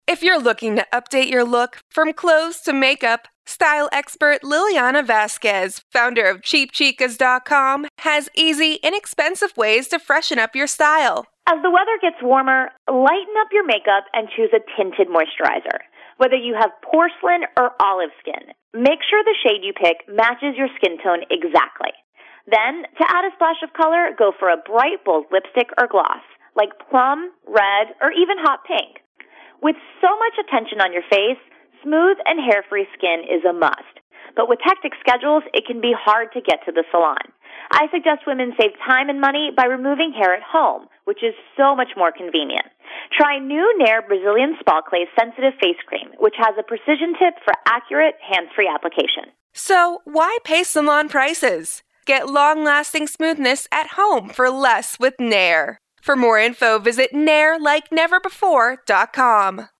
June 11, 2012Posted in: Audio News Release